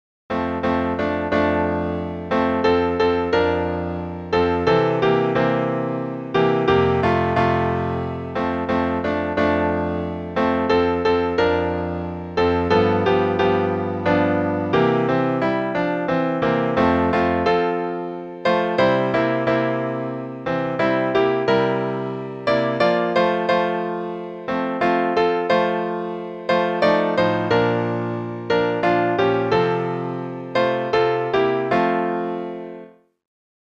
088 He Lifted Me (qn=90).mp3